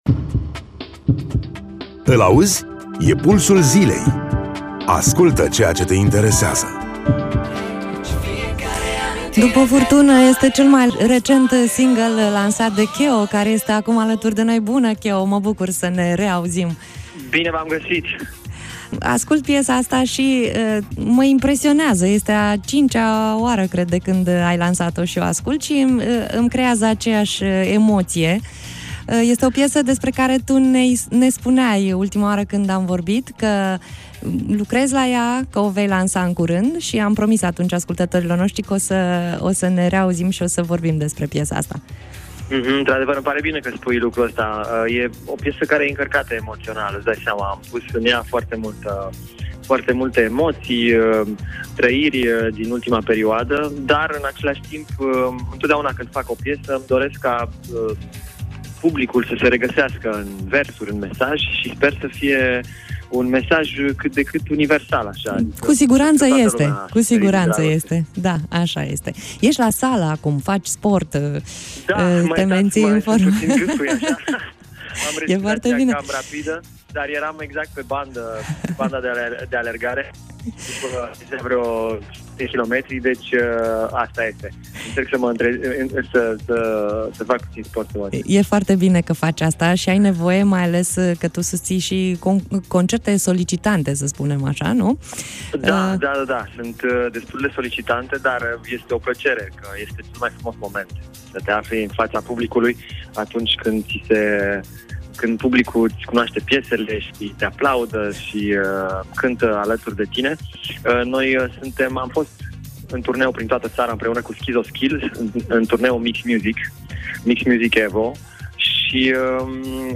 8-Oct-Interviu-Keo.mp3